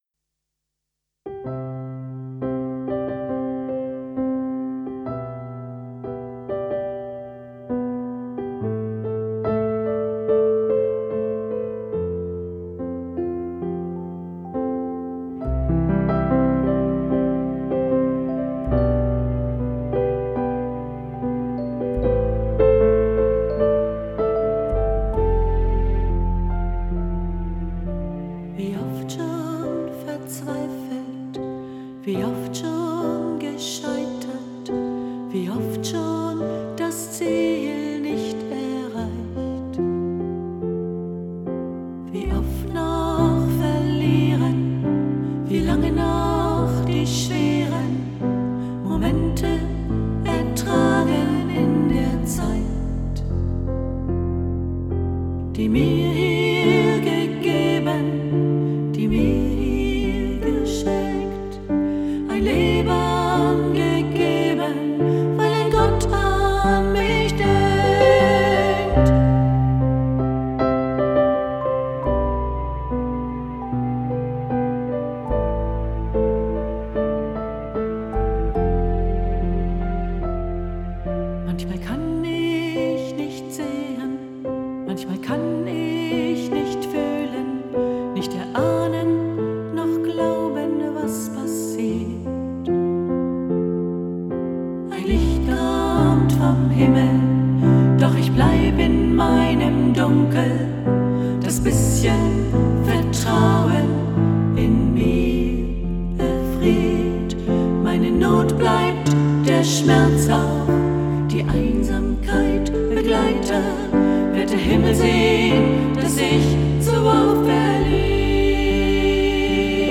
In der Bibel, dem himmlisch, menschlichen Kunstwerk wertvoller Gedanken und Geschichten mit einem ewigen Ausblick, finde ich jeden Tag Sinn und Hoffnung für mein Leben. Gott spricht zu uns Menschen und ich antworte ihm, meistens in Liedern.